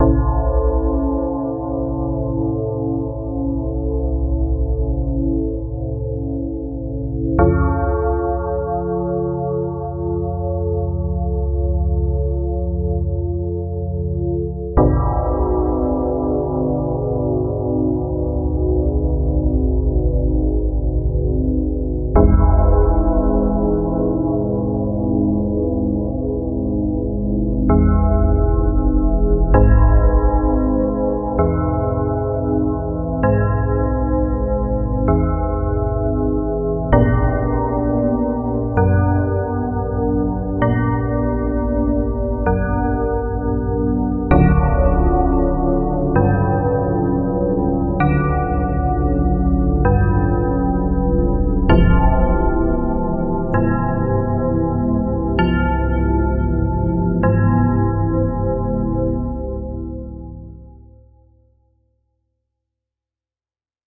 恐怖怪物的叫声
描述：恐怖怪物的叫声
标签： 怪异 闹鬼 语音 震颤 恐怖 幽灵 戏剧 忧虑 可怕的 嗓音 恶梦 可怕 险恶 悬念 怪物
声道立体声